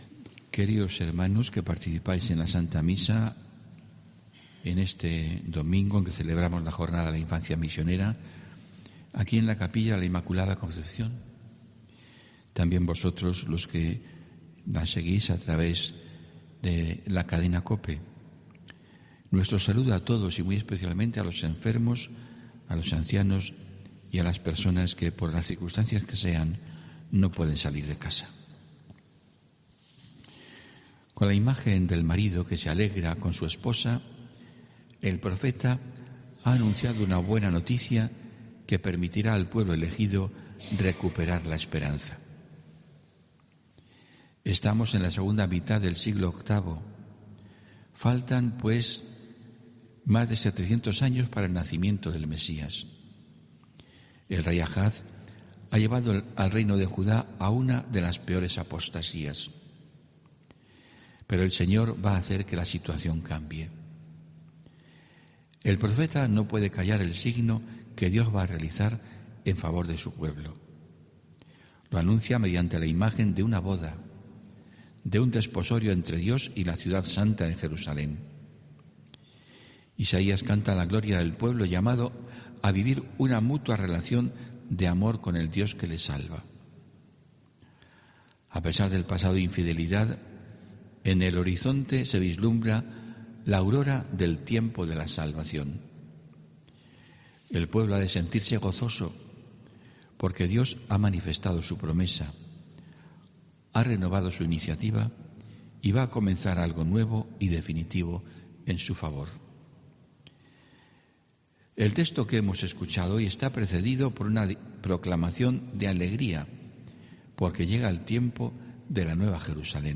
HOMILÍA 16 ENERO 2022